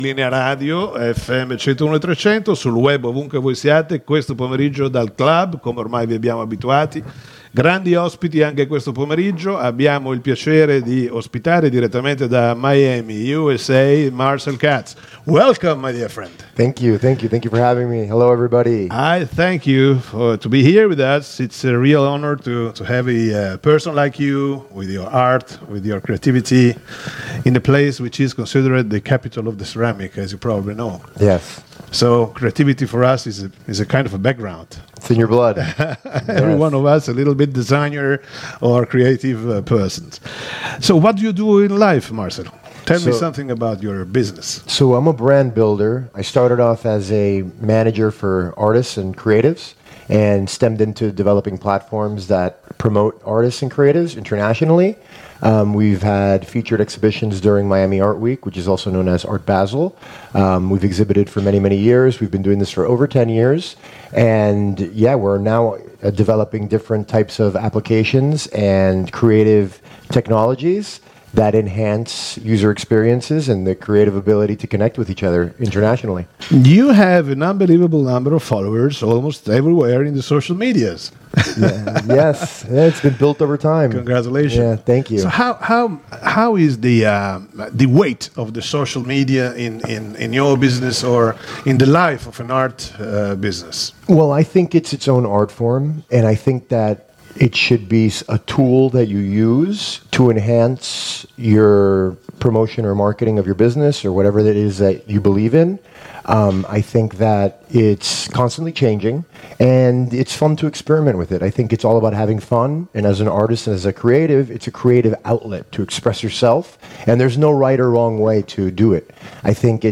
Intervista
Linea Radio al Clhub di viale XX Settembre a Sassuolo